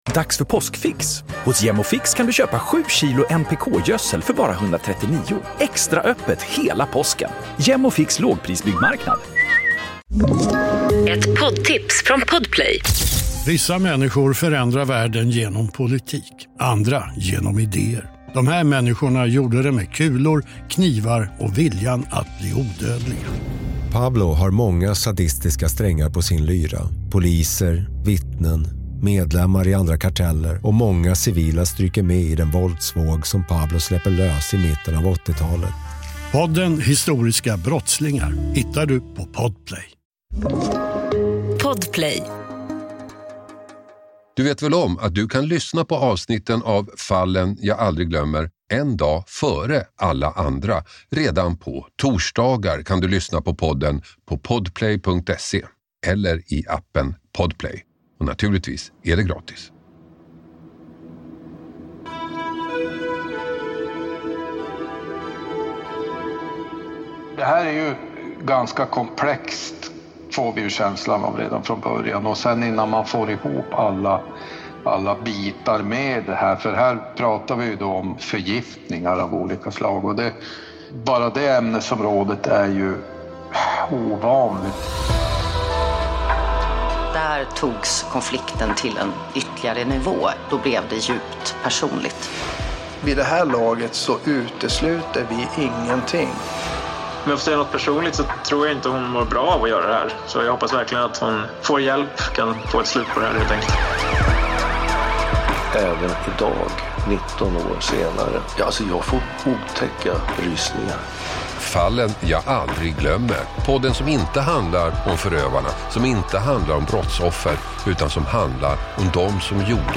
Hasse Aro intervjuar